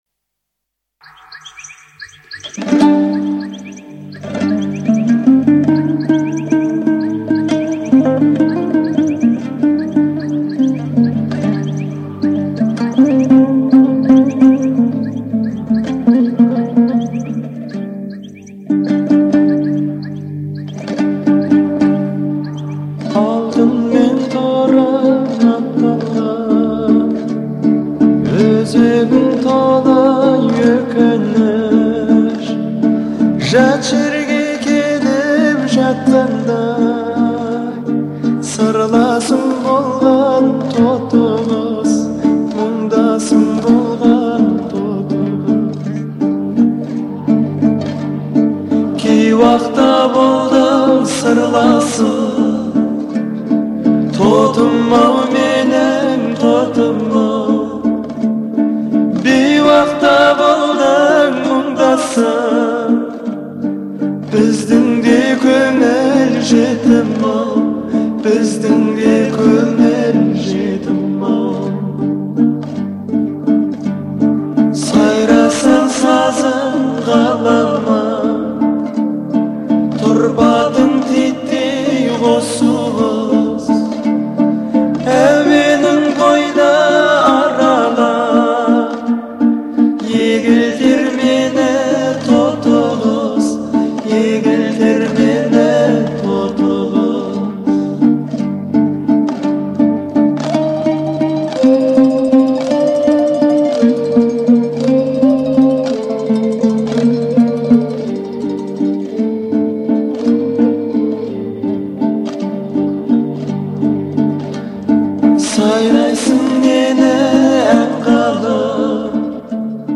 это яркая и мелодичная песня в жанре казахской поп-музыки